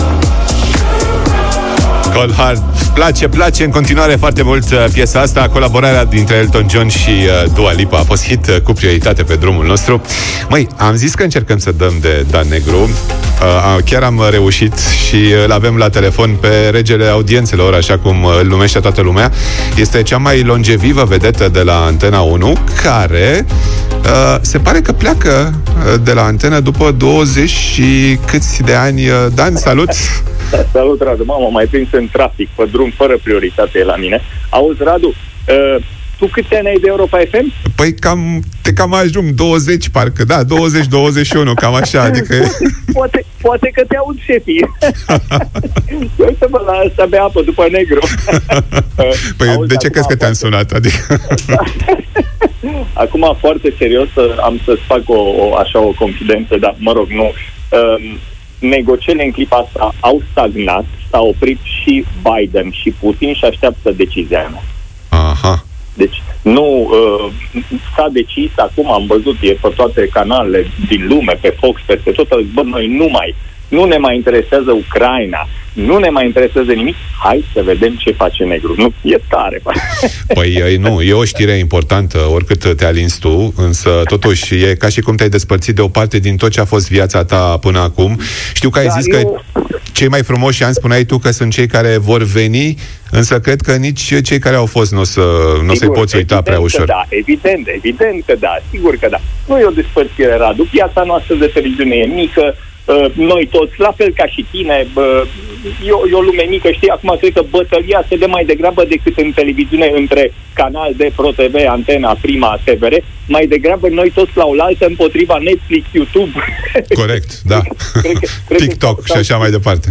Dan Negru a intrat în direct cu noi prin telefon, pe Drum cu Prioritate. Este cea mai longevivă vedetă de la Antena 1, care după 22 de ani părăsește postul și pleacă la Kanal D.